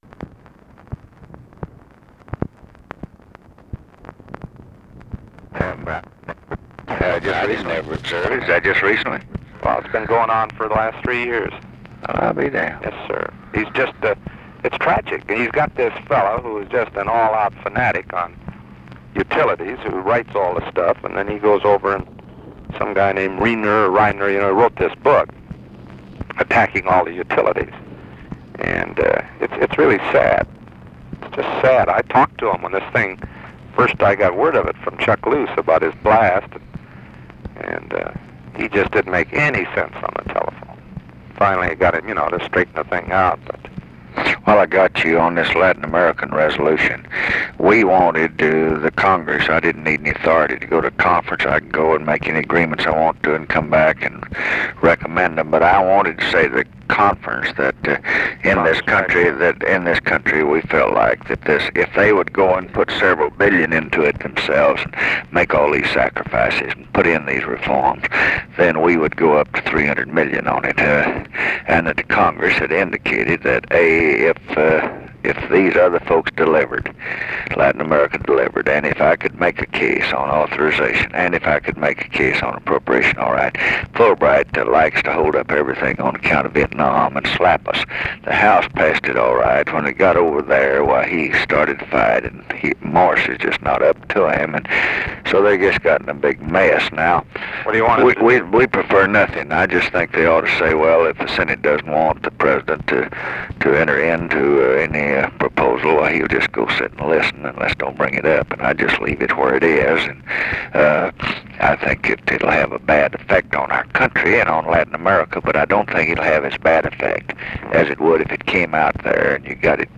Conversation with HENRY JACKSON, April 4, 1967
Secret White House Tapes